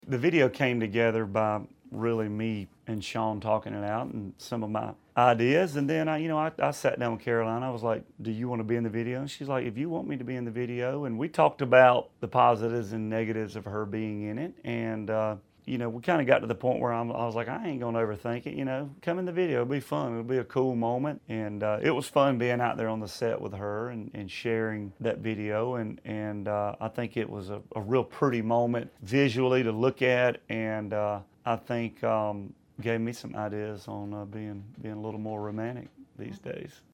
AUDIO: Luke Bryan talks about the video for his recent three-week No. 1 smash, “Crash My Party.”